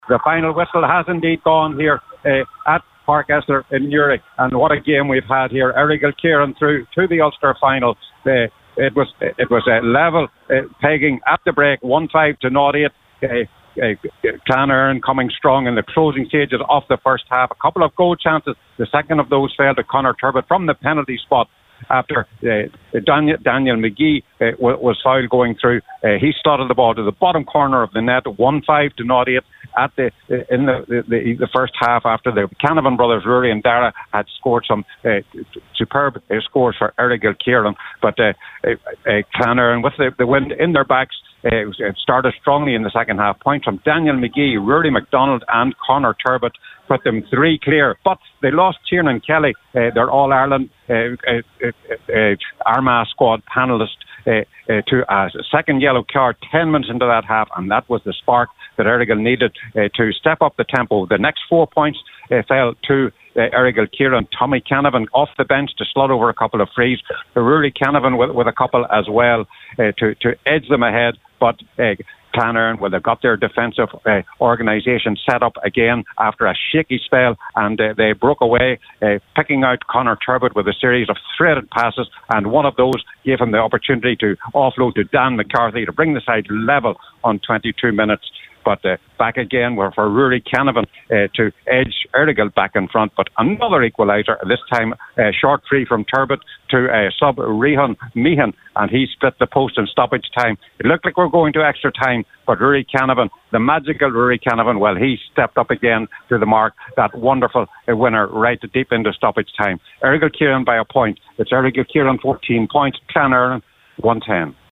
With the full time report from Pairc Esler